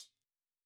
Hi Hat Hot Wind Blows.wav